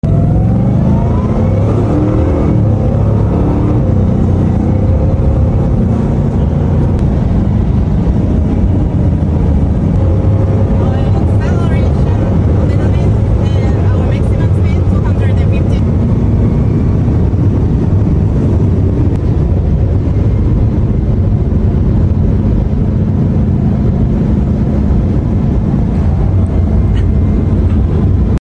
Bugatti Chiron acceleration! 🤯 Hankooktire sound effects free download